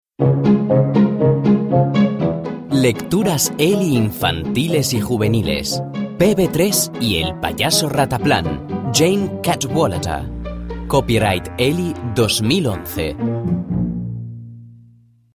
Speaker madrelingua spagnolo dalla voce calda e versatile.
Sprechprobe: eLearning (Muttersprache):
Native Spanish voice-artist with a warm and versatile voice.